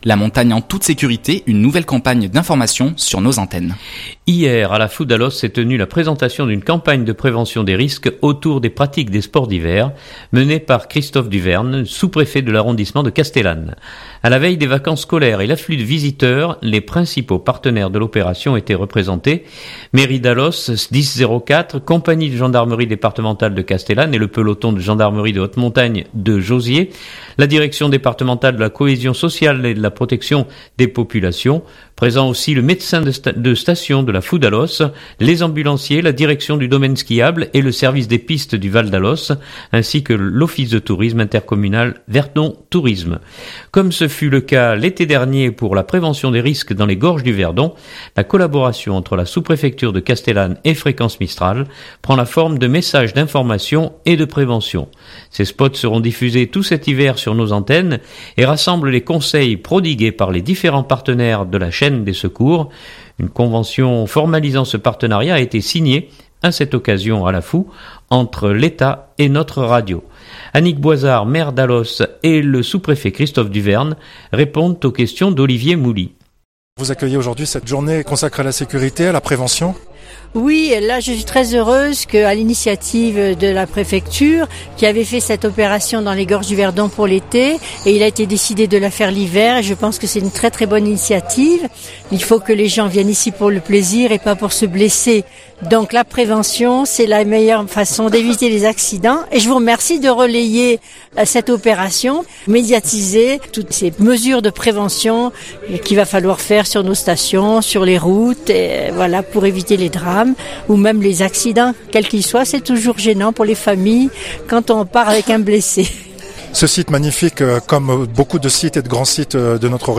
Hier à la Foux d'Allos s'est tenue la présentation d'une campagne de prévention des risques autour des pratiques des sports d'hiver, menée par Christophe Duverne sous-préfet de l'arrondissement de Castellane.